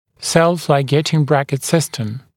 [self-laɪˈgeɪtɪŋ ‘brækɪt ‘sɪstəm][сэлф-лайˈгейтин ‘брэкит ‘систэм]самолигирующаяся брекет-система